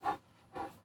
pant2.ogg